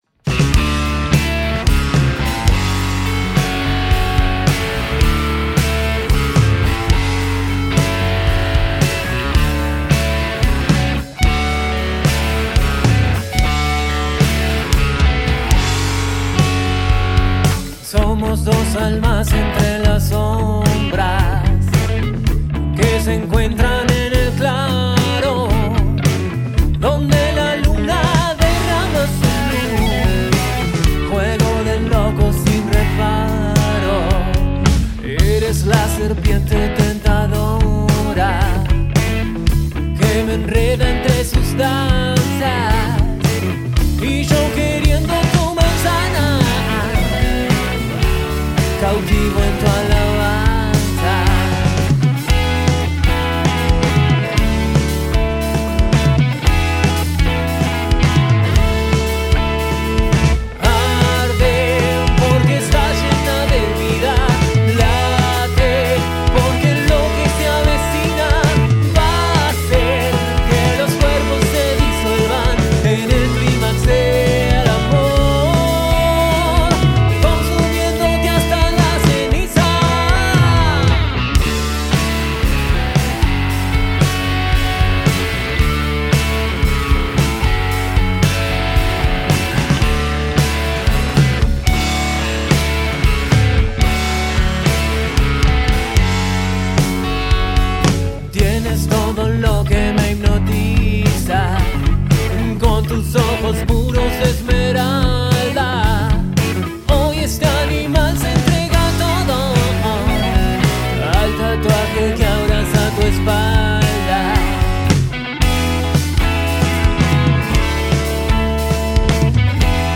Grabada en vivo el 1 de junio de 2025